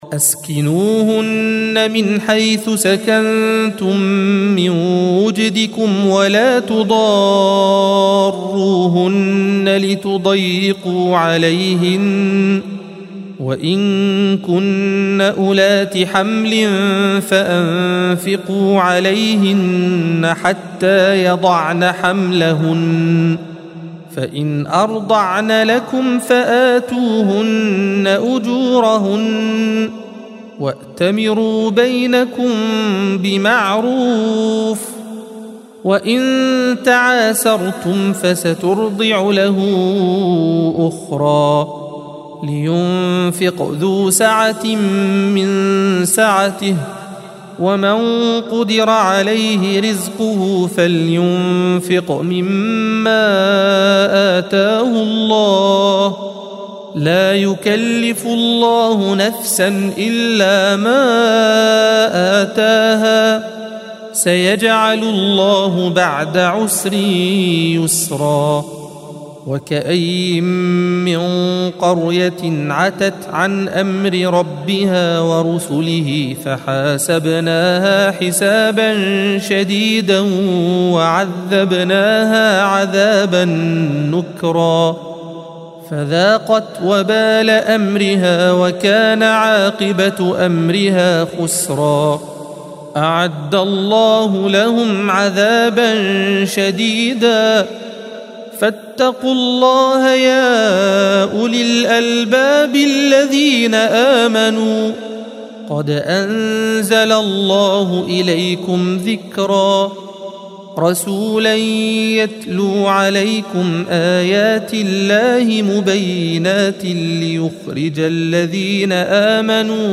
الصفحة 559 - القارئ